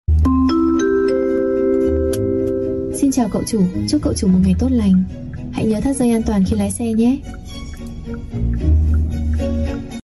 Âm thanh Xin Chào Cậu Chủ Carplay (Giọng nữ)
Thể loại: Tiếng chuông, còi
am-thanh-xin-chao-cau-chu-carplay-giong-nu-www_tiengdong_com.mp3